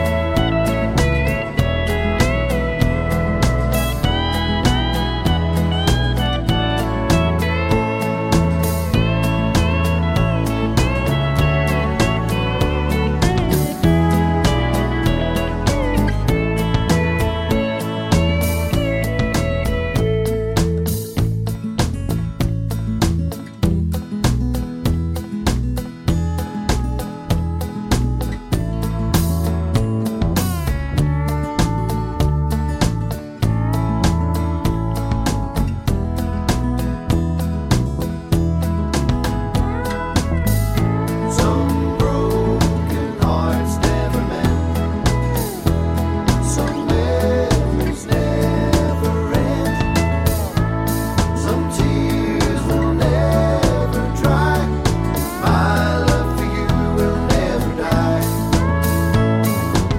no Backing Vocals Country (Male) 2:35 Buy £1.50